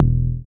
BAS_DowwwnBa1.wav